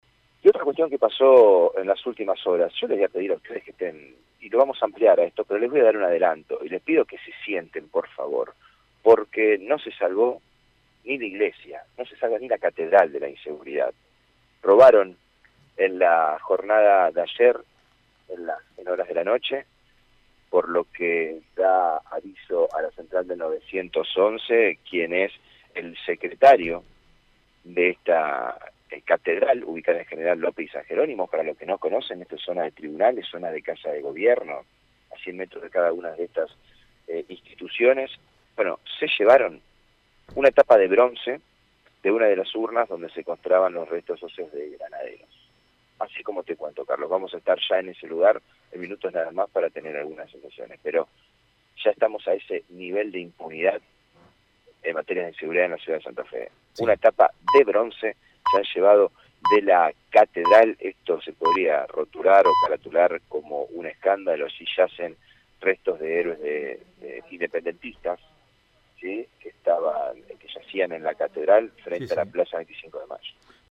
informe-robo-en-catedral.mp3